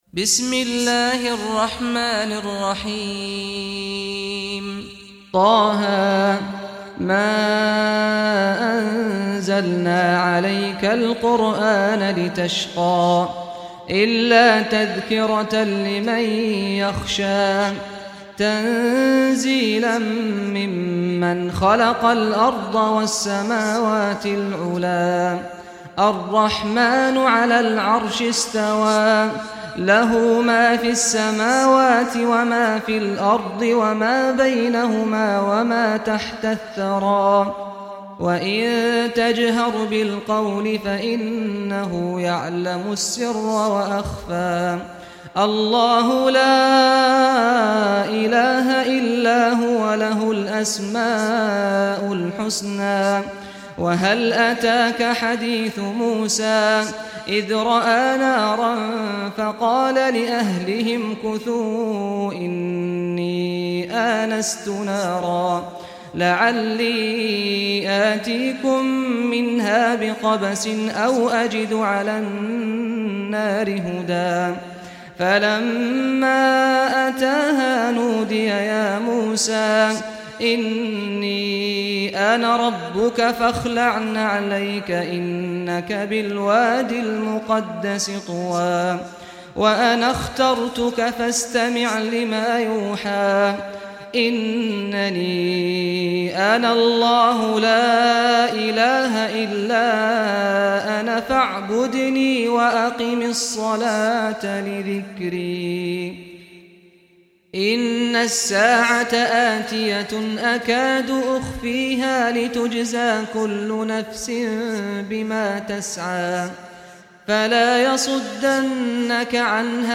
Surah Taha Recitation by Sheikh Saad al Ghamdi
Surah Taha, listen or play online mp3 tilawat / recitation in Arabic in the beautiful voice of Imam Sheikh Saad al Ghamdi.
20-surah-taha.mp3